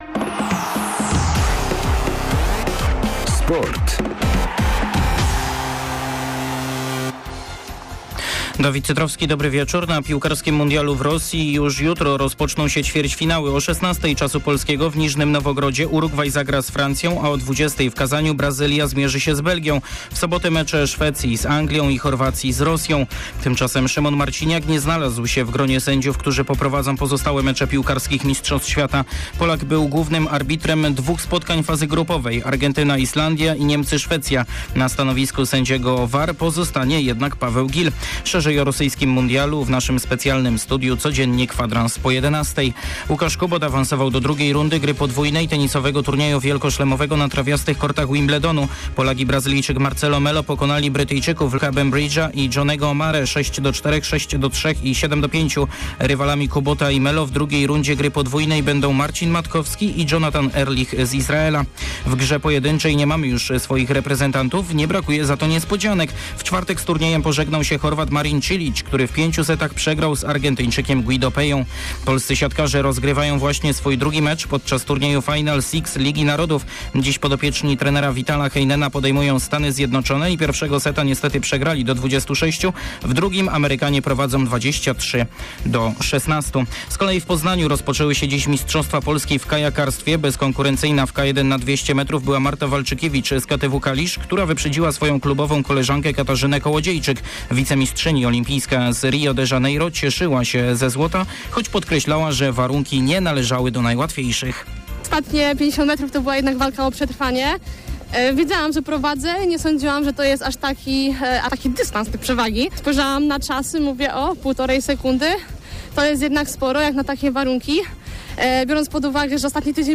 05.07 serwis sportowy godz. 19:05